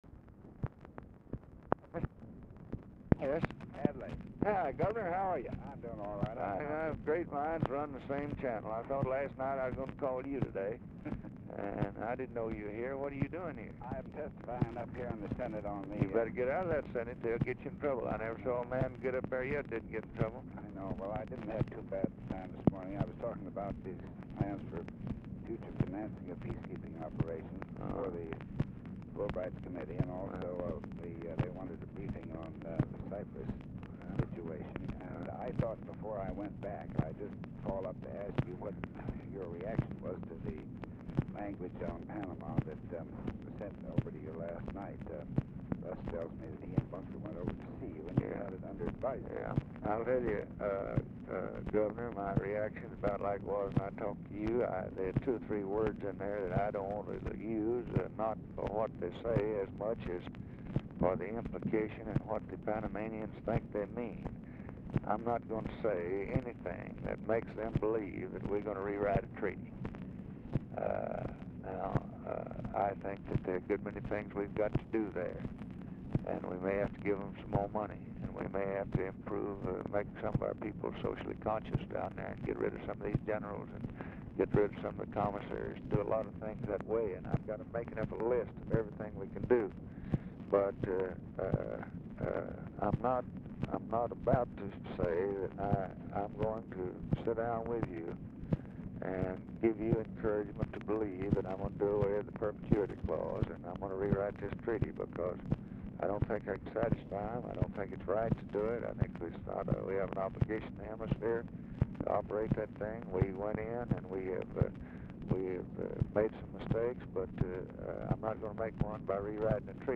Telephone conversation # 2206, sound recording, LBJ and ADLAI STEVENSON
POOR SOUND QUALITY; RECORDING ENDS ABRUPTLY
Format Dictation belt